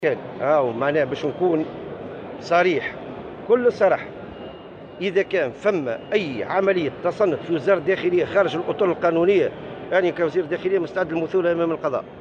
ونفى الوزير في تصريح لمراسلة "الجوهرة أف أم" على هامش فعاليات الصالون الدولي للأجهزة والتقنيات الحديثة للسلامة كل الاتهامات الموجهة للوزارة بخصوص التنصت على المكالمات الهاتفية.